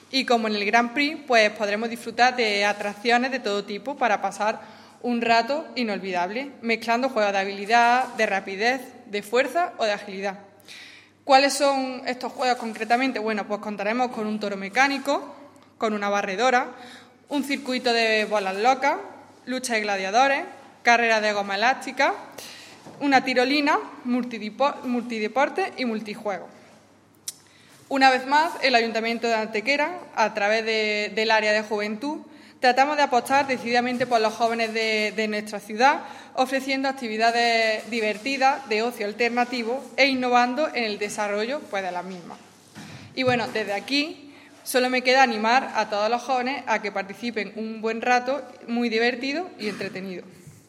La concejal delegada de Juventud, Marta González, ha presentado hoy ante los medios de comunicación una nueva actividad promovida como alternativa de ocio, diversión y entretenimiento para los más jóvenes.
Cortes de voz